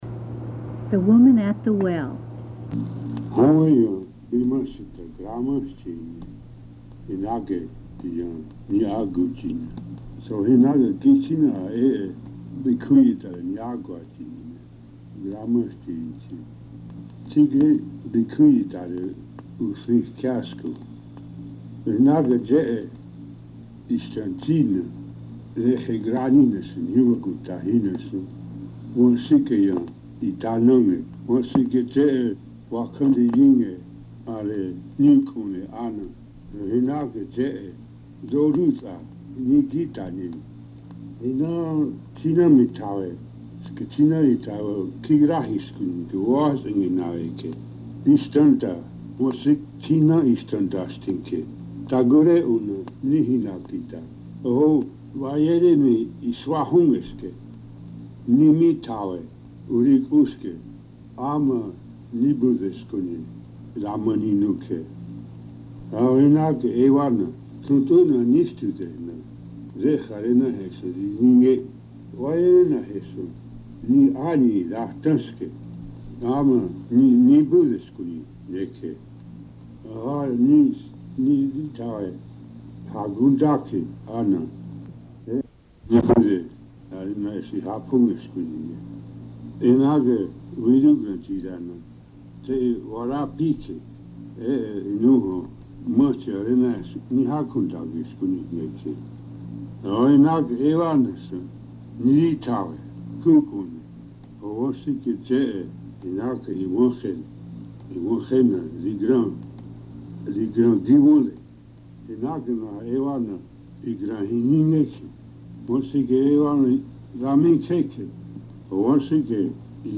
Note: The English Translation is presented here for the benefit of those wishing to hear the Ioway - Otoe - Missouria Language spoken by Native speakers, and does not represent any efforts to promote any particular religious views or denominations.